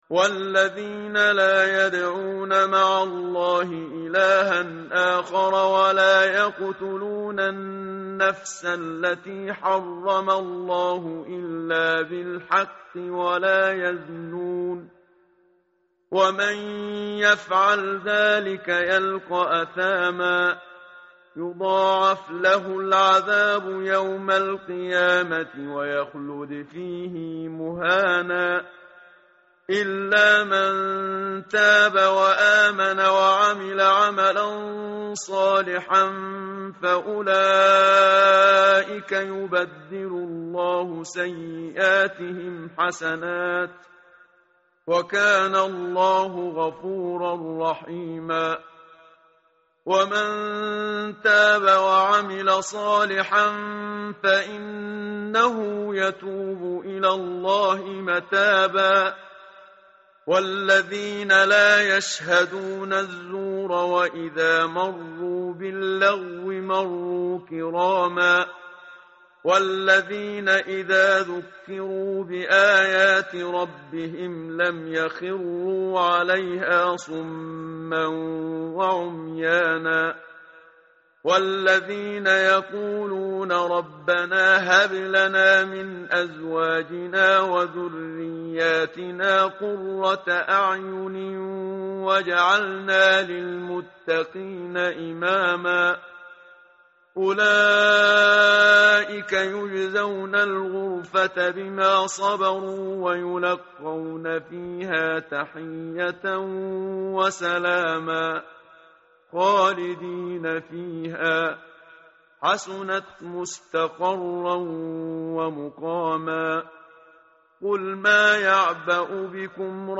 tartil_menshavi_page_366.mp3